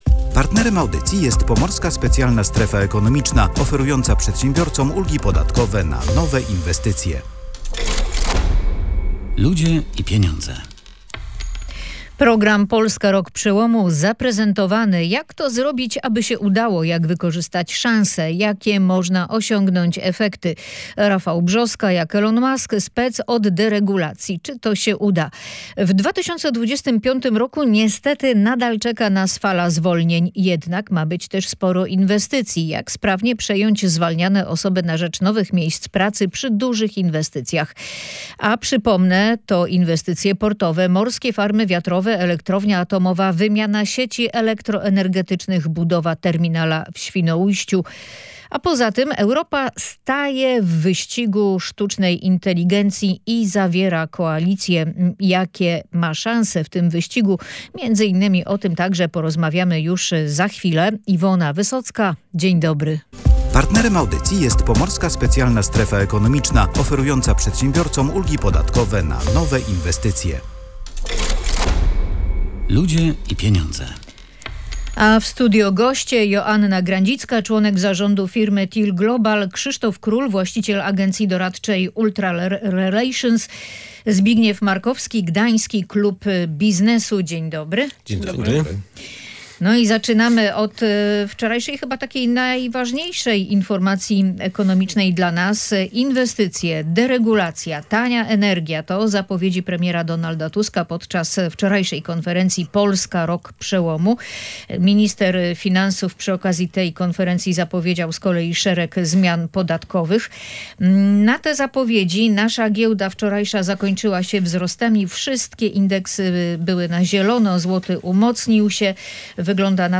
Główne założenia programu to inwestycje, deregulacja, tania energia i 650 miliardów złotych do wydania w tym roku. Rozmowę